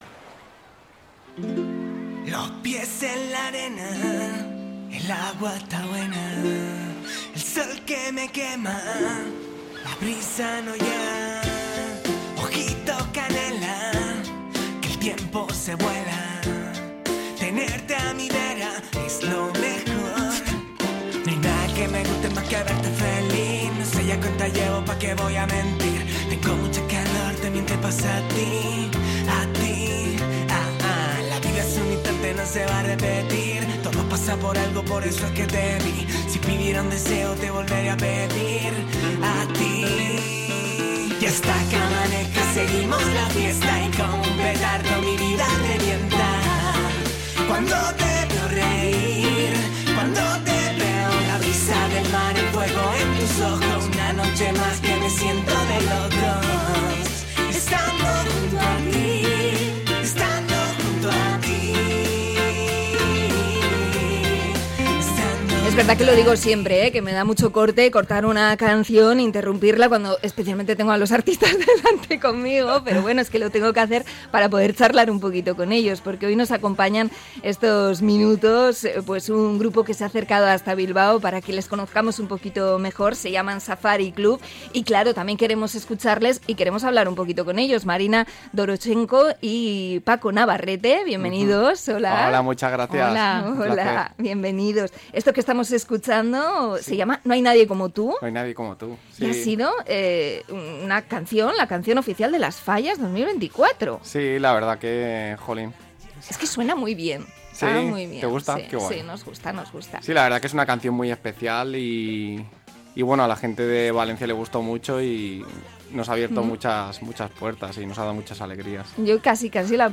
Entrevista al dúo Safari Club